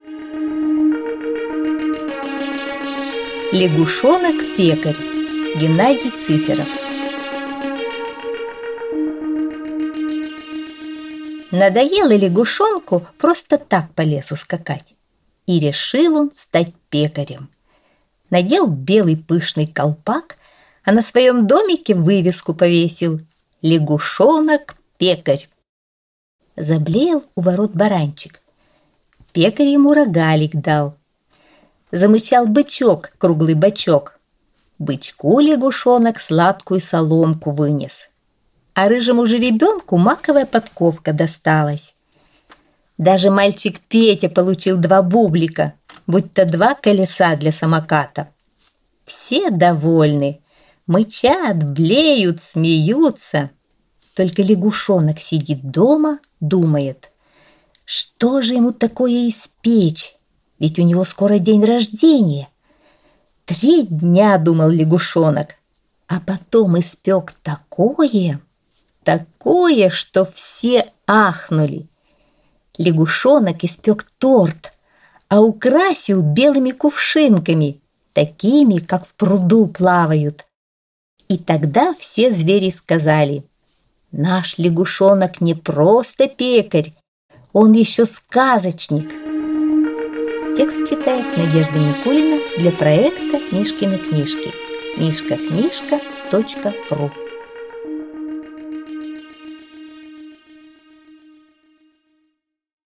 Аудиосказка «Лягушонок-пекарь -»